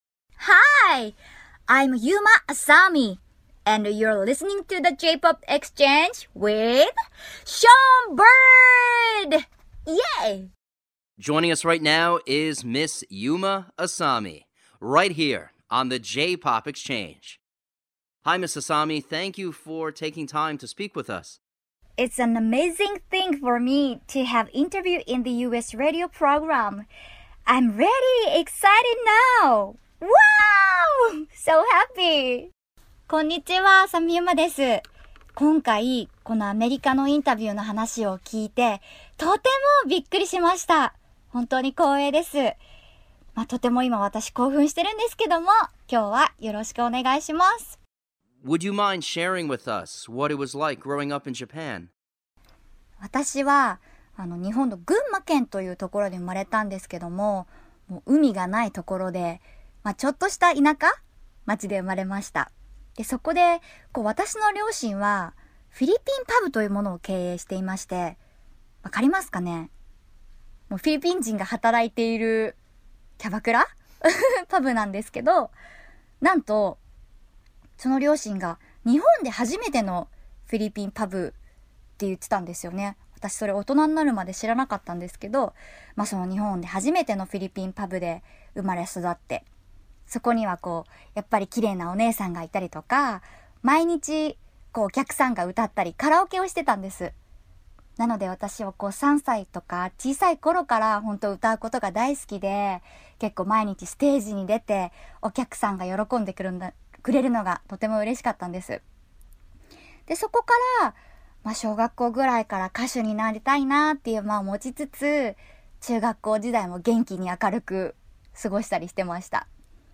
Yuma Asami Radio Interview:(Without translation voice-over - includes transcript)
Portions of the interview in which she spoke in English are in blue.
JP_Yuma_Asami_JPop_Exchange_Exclusive_Interview.mp3